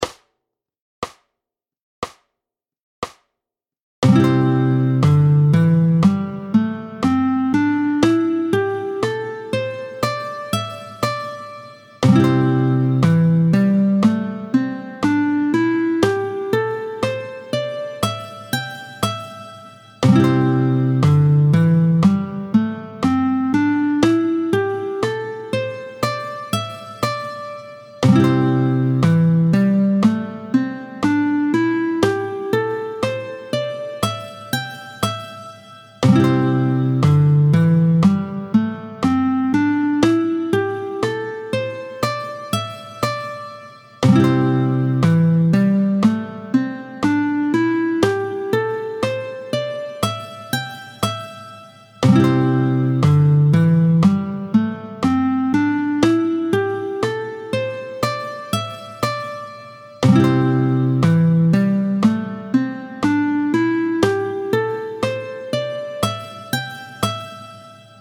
32-05 Do majeur doigtés 3 et 4, tempo 60